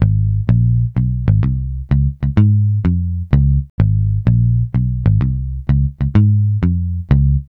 Swingerz 6 Bass-G#.wav